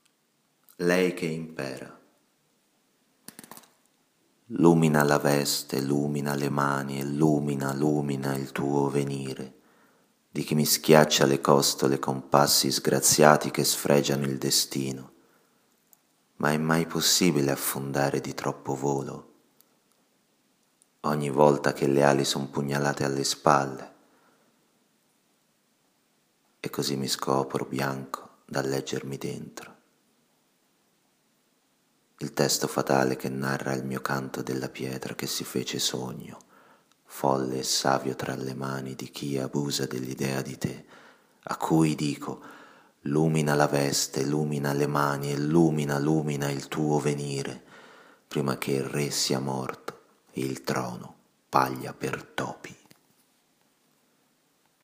Poesia e musica, ,